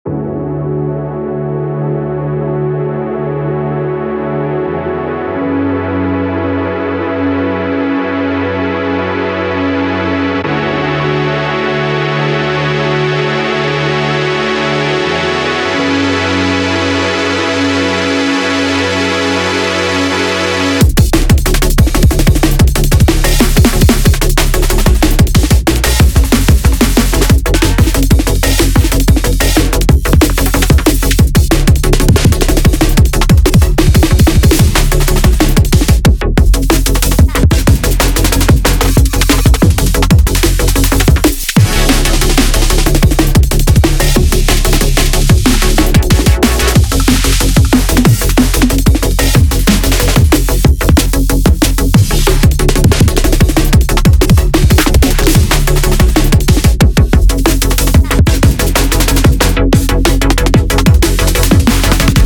breakcore
Sounds more like drum n Bass to me, breakcore usually spams the shit out of the amen, think, yeh! woo!, and Italian breaks.
very IDM